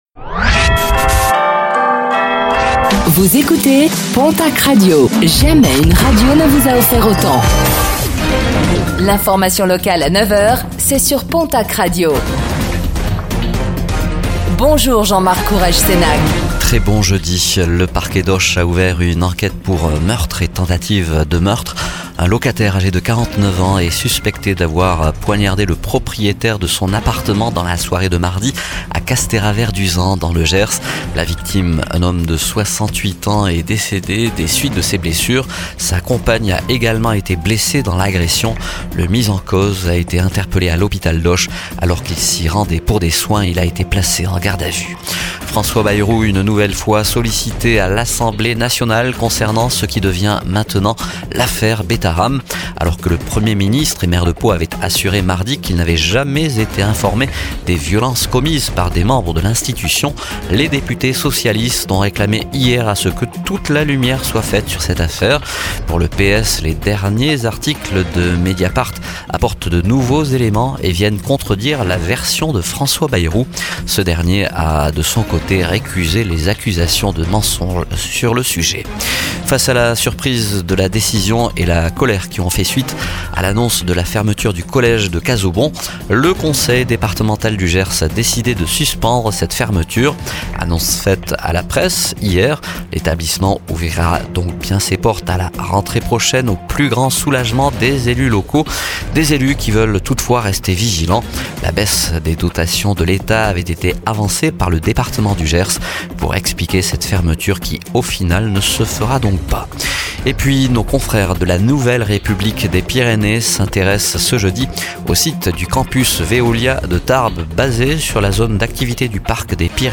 Infos | Jeudi 13 février 2025